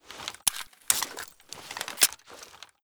pm_reload.ogg